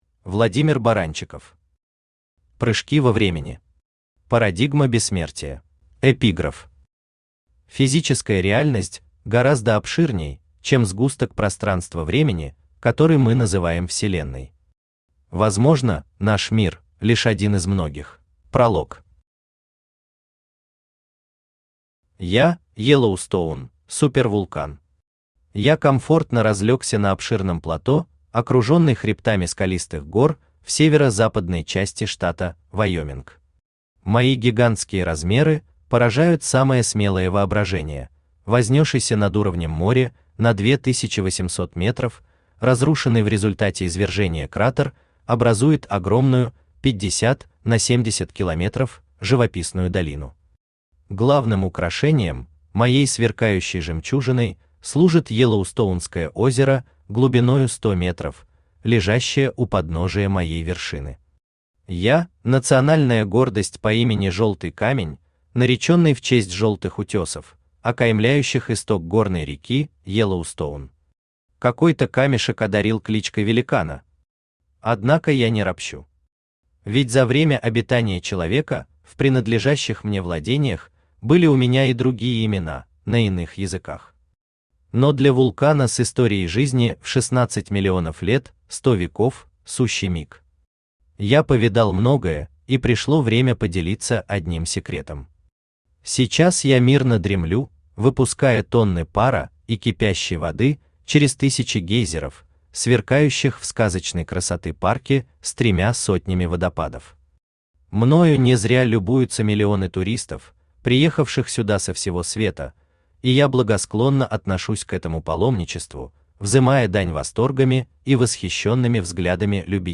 Парадигма бессмертия Автор Владимир Иванович Баранчиков Читает аудиокнигу Авточтец ЛитРес.